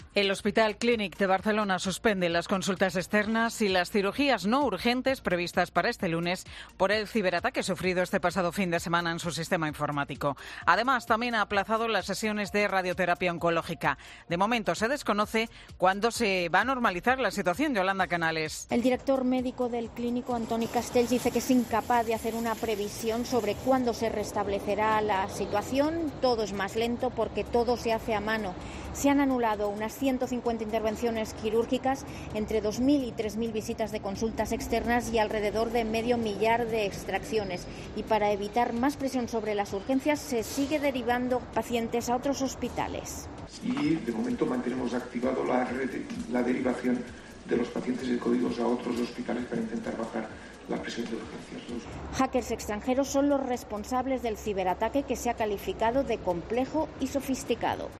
En Mediodía COPE hemos escuchado a algunos pacientes que han acudido a su cita al no enterarse de que había sido cancelada.